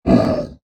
shopkeep sigh
bloodclot-exhale.ogg